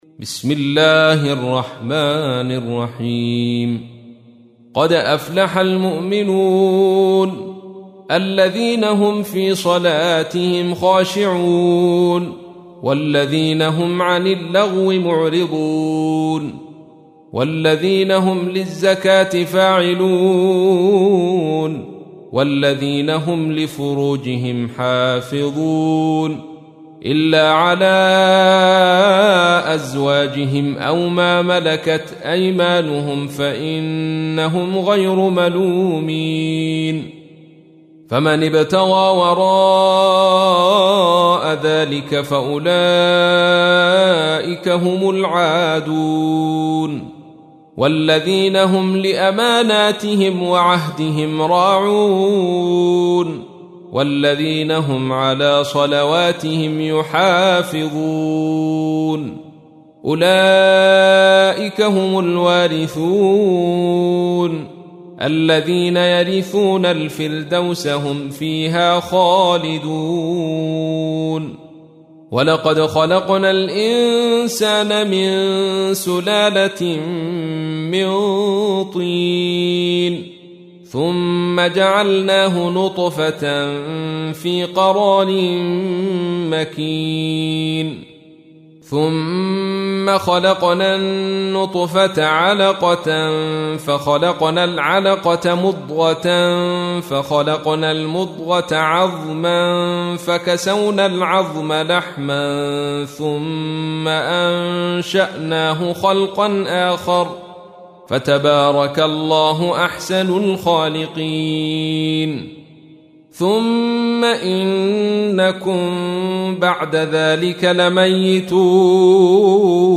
تحميل : 23. سورة المؤمنون / القارئ عبد الرشيد صوفي / القرآن الكريم / موقع يا حسين